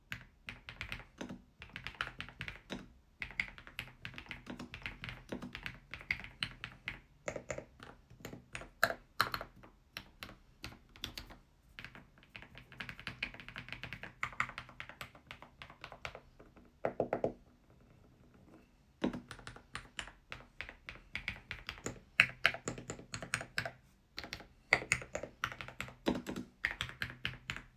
Szczególną uwagę zwraca kultura pracy podczas intensywnego użytkowania – przełączniki generują stonowany dźwięk, co docenią osoby pracujące w miejscach, gdzie wysoki poziom hałasu mógłby przeszkadzać innym.
Switche i dźwięki
Solidne keycapy PBT dodatkowo wzmacniają pozytywne wrażenia, oferując przyjemny dźwięk podczas użytkowania. Stabilizatory zasługują na szczególne wyróżnienie – są ciche i nie sprawiają problemów nawet przy intensywnym używaniu większych klawiszy, takich jak spacja czy enter.